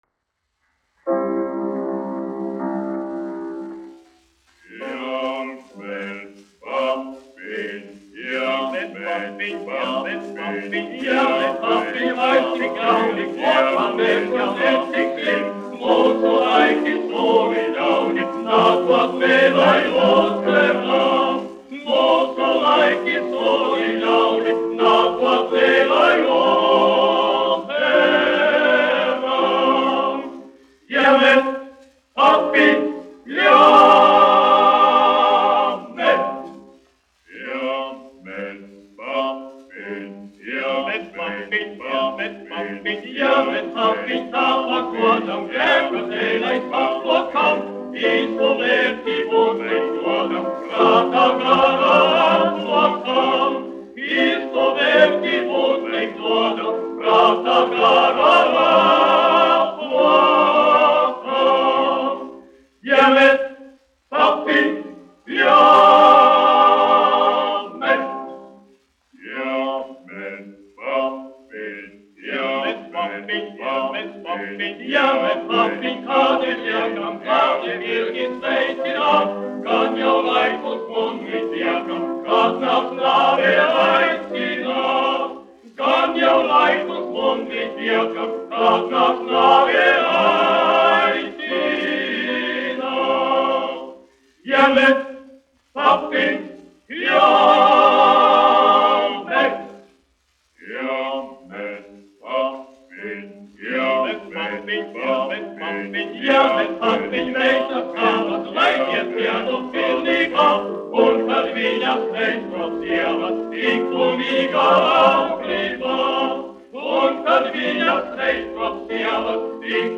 1 skpl. : analogs, 78 apgr/min, mono ; 25 cm
Vokālie ansambļi ar klavierēm
Latvijas vēsturiskie šellaka skaņuplašu ieraksti (Kolekcija)